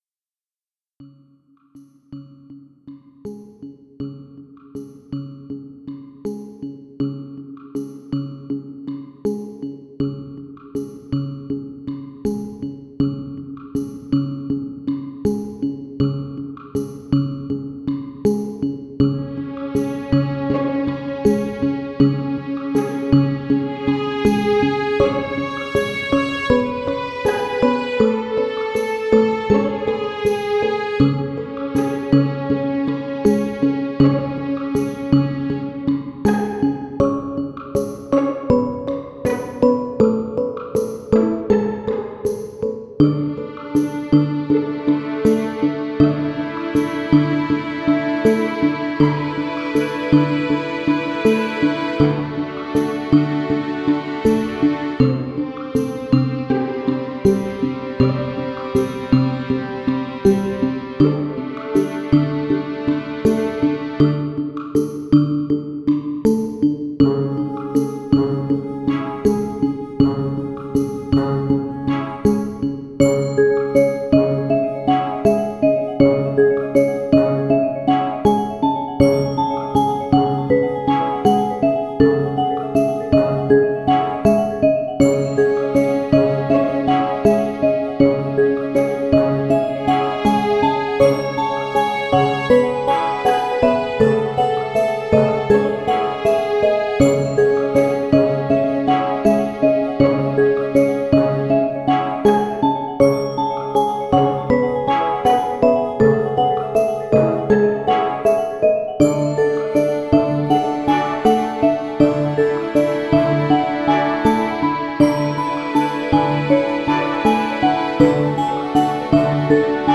Electronic / 2009